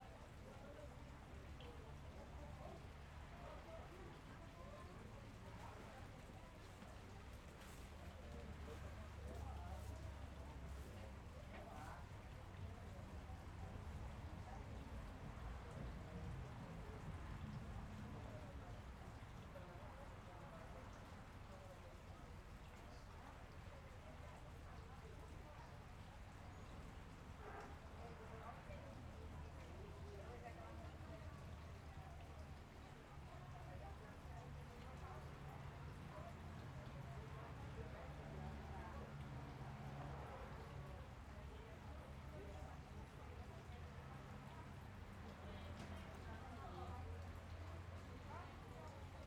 Ambiencia viveiro com pessoas trabalhando, fonte de agua e transito longe Fonte de água , Pessoas , Trânsito , Viveiro , Vozerio Brasília Stereo
CSC-04-184-LE - Ambiencia viveiro com pessoas trabalhando, fonte de agua e transito longe.wav